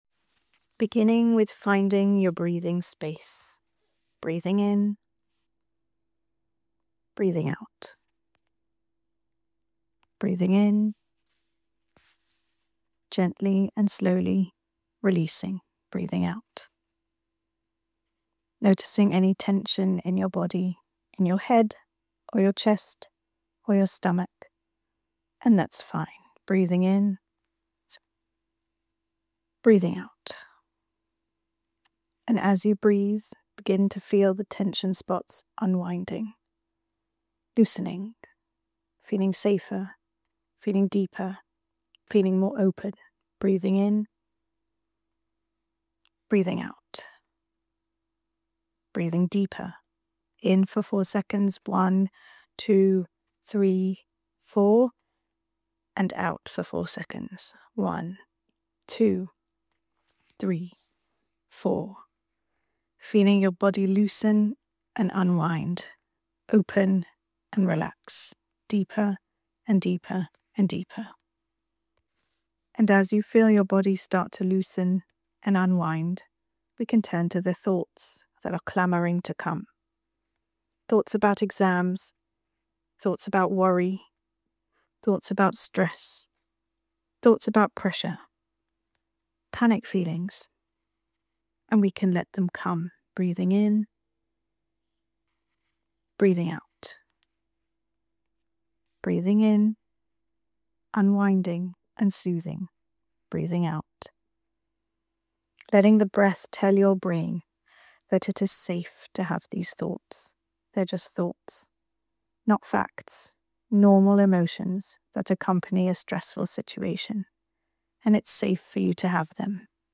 Enjoy and feel free to share TorahPsych’s collection of breathing and visualisation exercises to help you calmly tolerate feelings.
Exams mindfulness